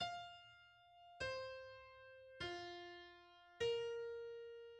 klinkt een vervormde elektrische gitaar als Henry Fonda